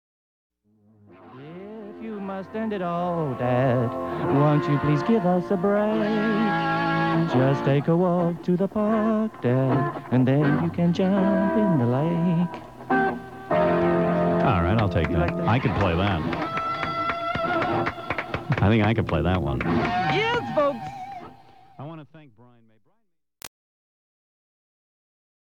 John_Deacon_Singing.mp3